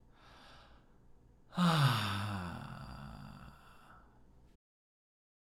まずは、「え」か「あ」の言葉で大きくため息（声あり）をついて、伸ばした最後の小さい声の中に、緩いじりじり音が自然に混ざってこないか探します。
※見本音声(「あ」の音)